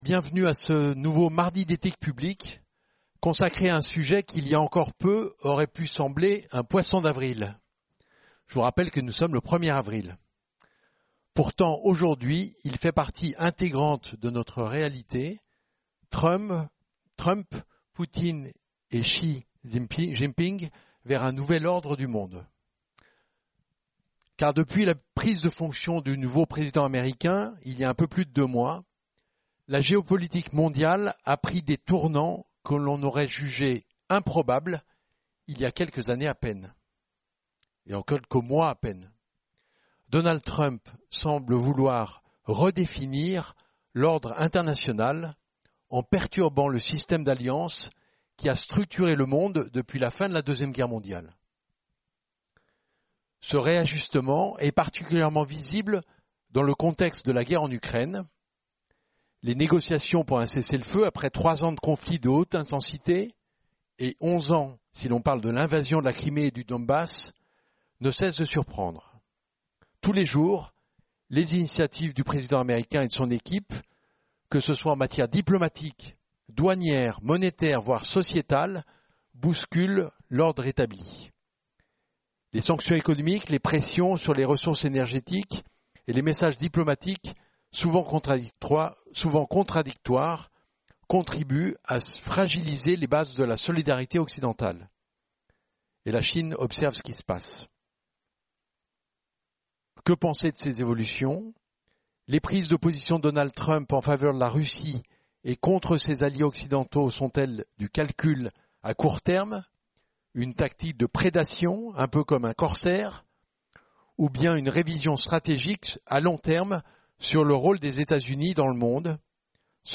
Débat